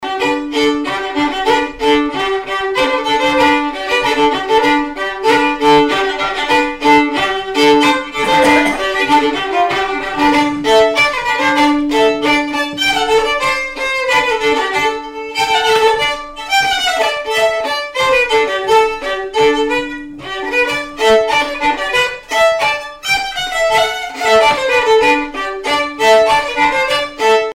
danse : aéroplane
Répertoire de bal au violon et accordéon
Pièce musicale inédite